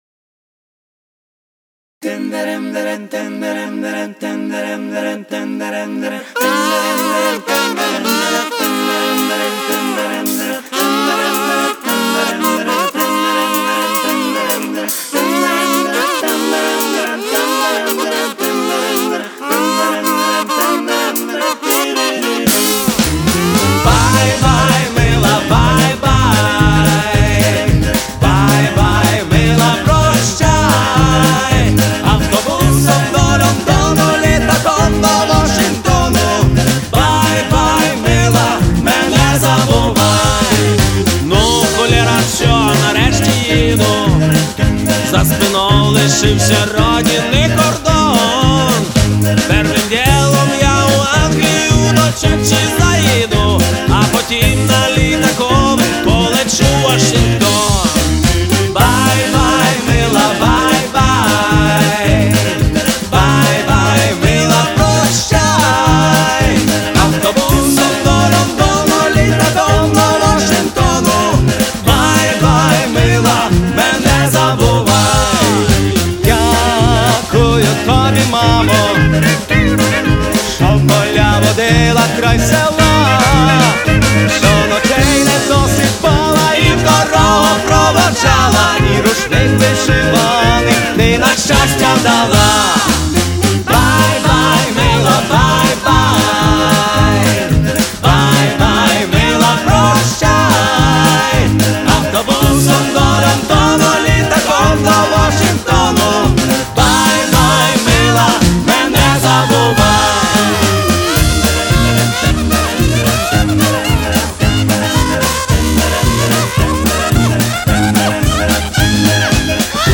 Стиль: Rock & Roll